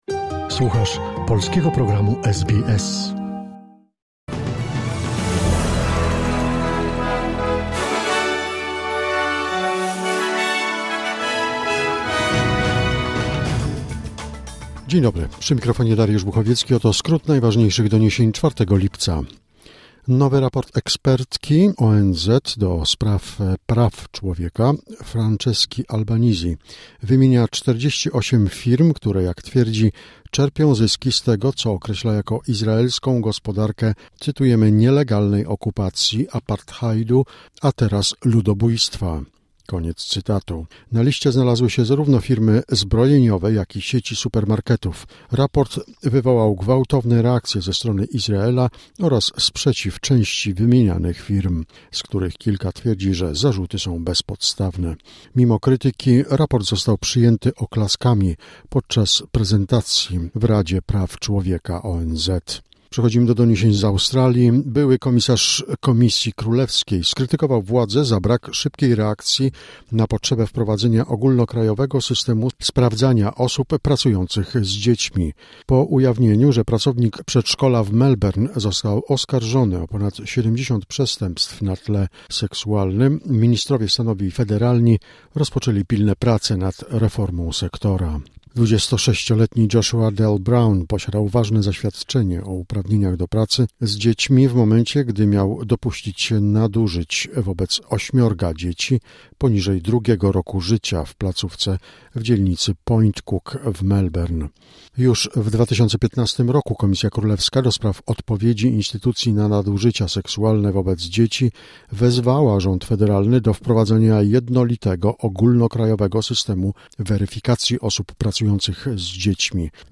Wiadomości 4 lipca SBS News Flash